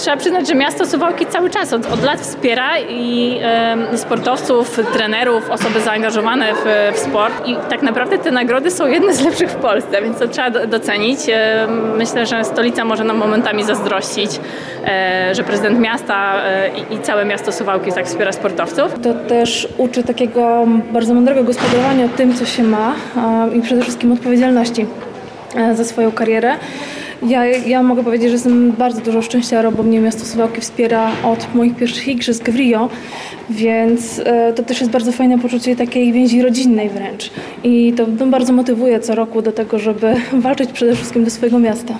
Jak mówiły Joanna Mendak, paraolimpijska multimedalistka w pływaniu oraz Maria Andrejczyk, olimpijska medalistka w rzucie oszczepem, wsparcie sportowców w Suwałkach stoi na bardzo wysokim poziomie. W ocenie Joanny Mendak sportowcy z innych miast mogą nawet zazdrościć suwalczanom.